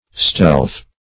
Stealth \Stealth\ (st[e^]lth), n. [OE. stal[thorn]e. See